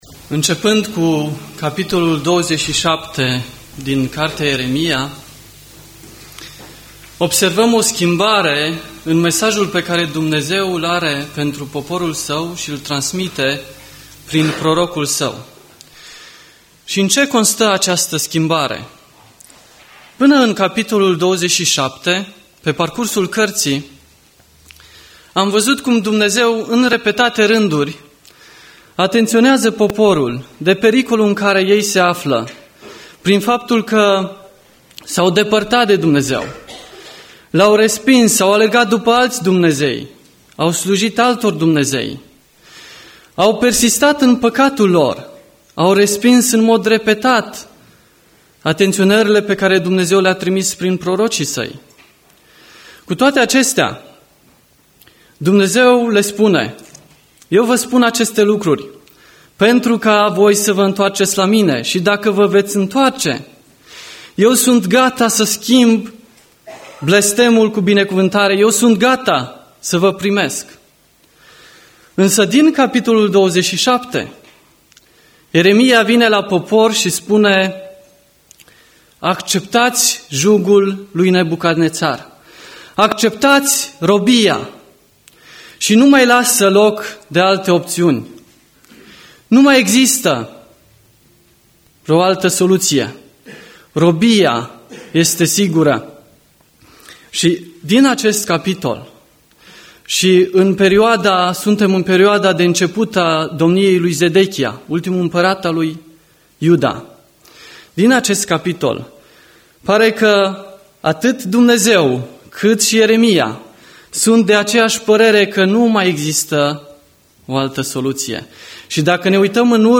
Predica Exegeza - Ieremia 30-31